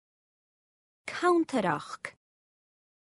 Web LearnGaelic Dictionary [Find canntaireachd ] & Amazon AWS (pronunciation).